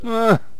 archer_die3.wav